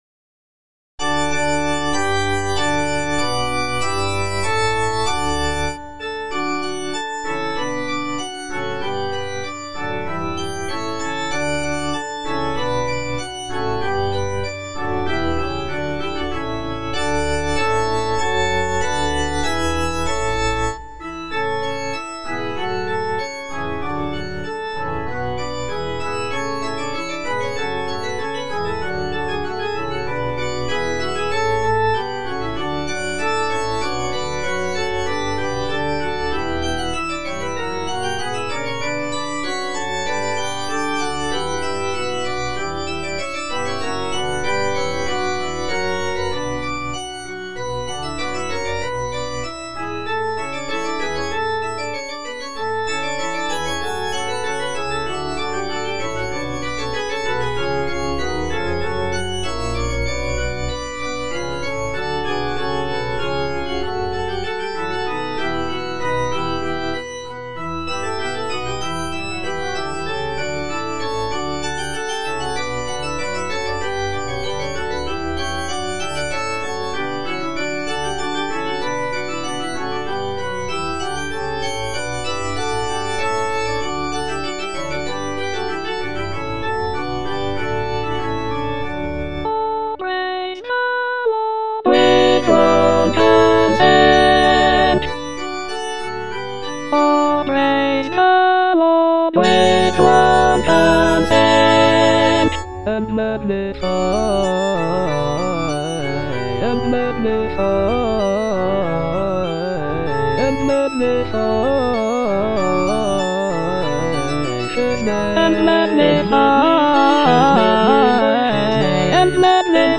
Choralplayer playing O praise the Lord with one consent - Chandos anthem no. 9 HWV254 (A = 415 Hz) by G.F. Händel based on the edition CPDL #08760
G.F. HÄNDEL - O PRAISE THE LORD WITH ONE CONSENT - CHANDOS ANTHEM NO.9 HWV254 (A = 415 Hz) O praise the Lord - Alto (Emphasised voice and other voices) Ads stop: auto-stop
The work is typically performed with historical performance practices in mind, including the use of a lower tuning of A=415 Hz to replicate the sound of Handel's time.